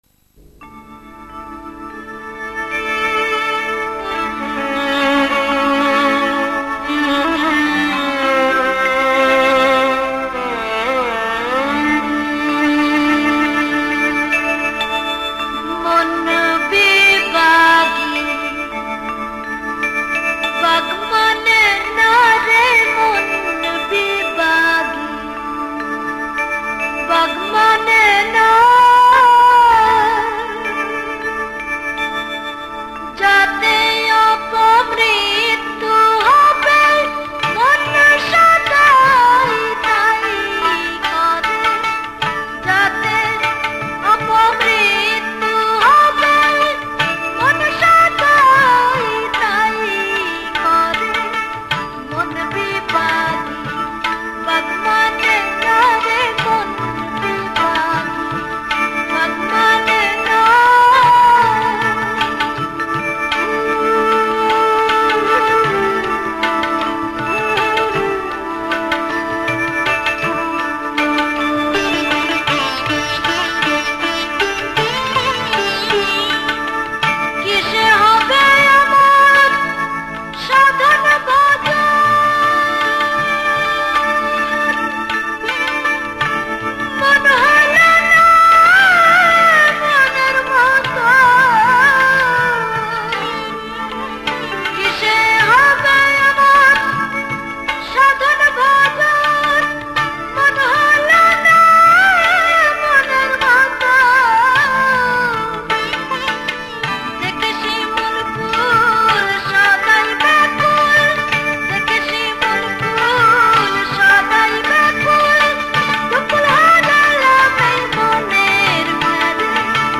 Bengali Kalam